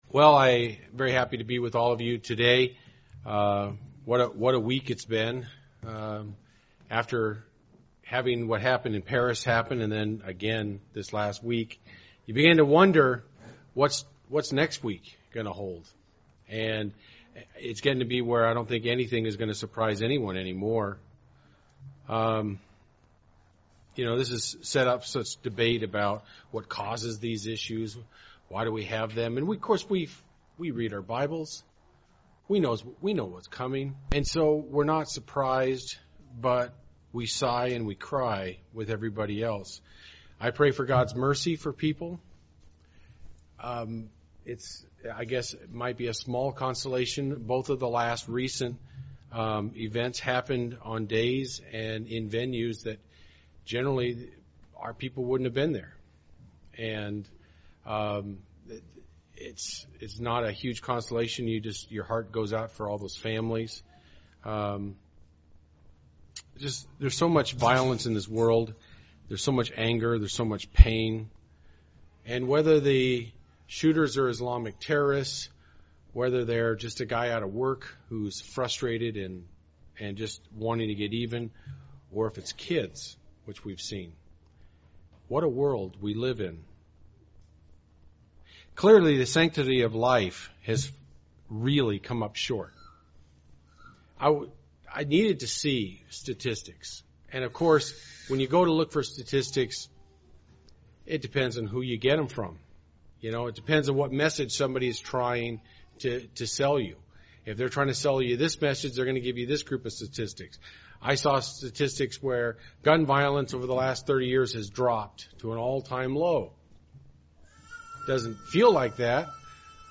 UCG Sermon Transcript Read more Notes On November 13th, Paris was attacked by terrorist and the lives of 130 people were lost and almost 400 others injured.